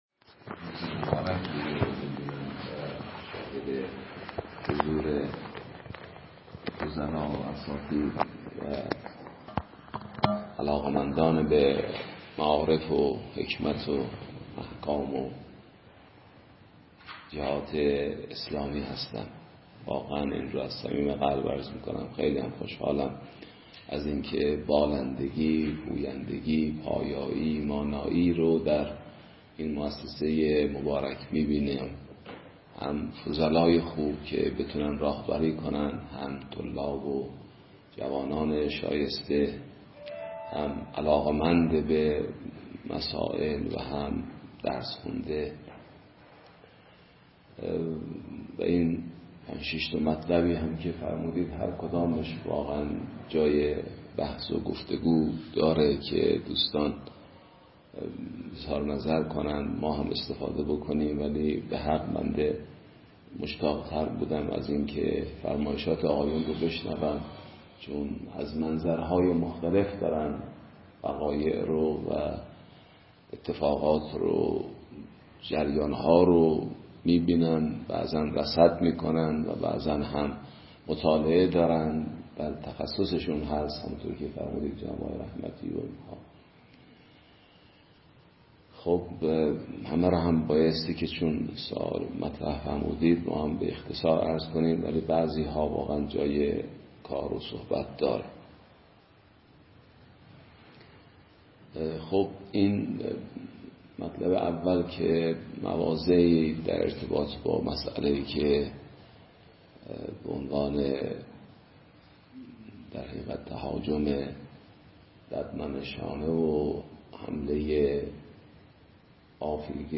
در این دیدار که در محل حسینیه دماوند برگزار شد